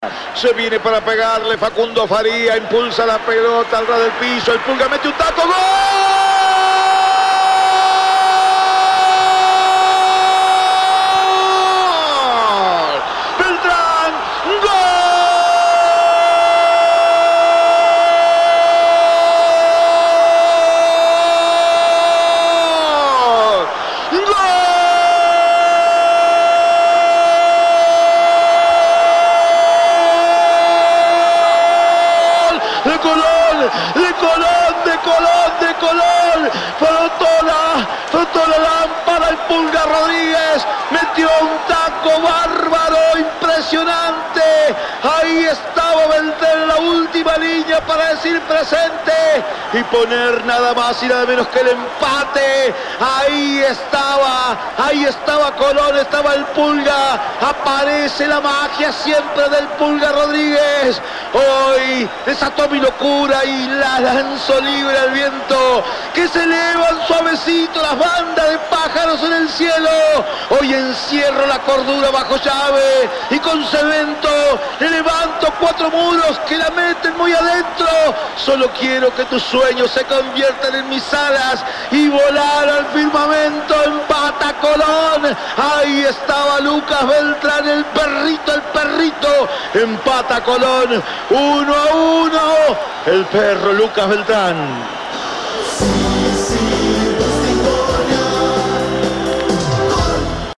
GOL-DE-COLÓN-01-EDITADO.mp3